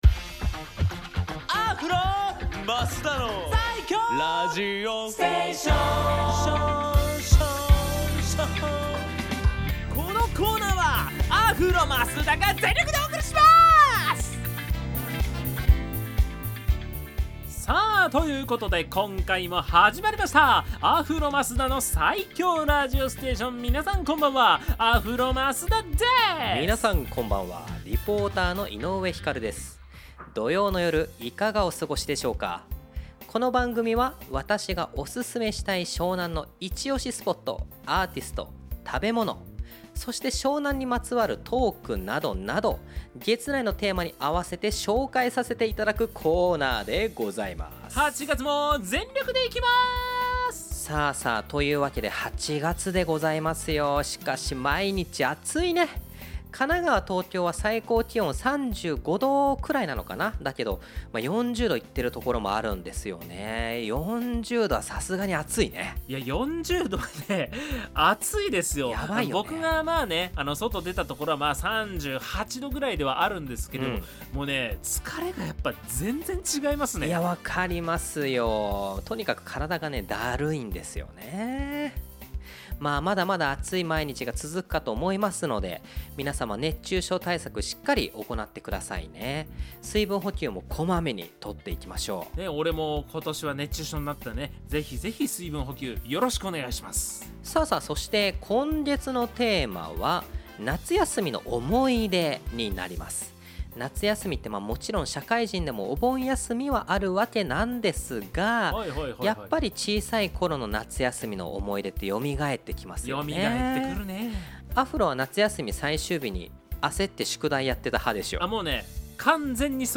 放送音源はこちら